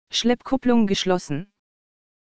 Diesen sind wie oben beschrieben ebenfalls mit dem online tool